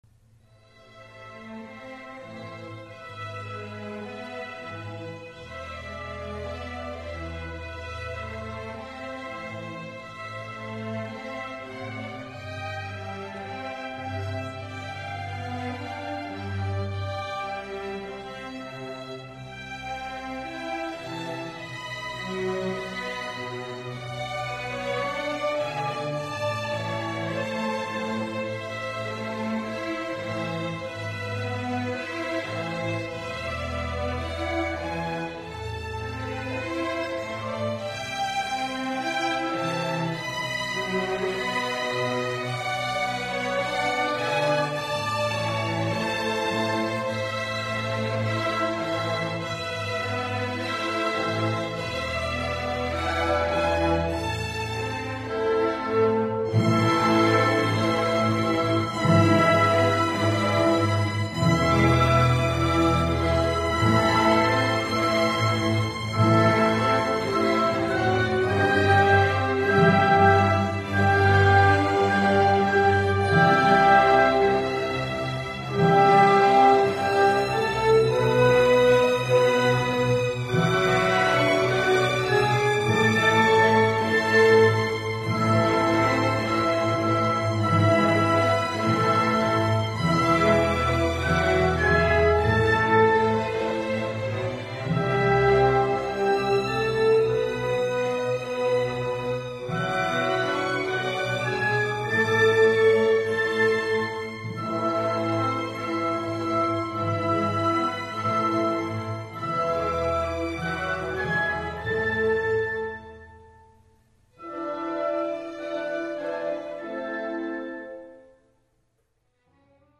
Allegretto. 1.8 MB.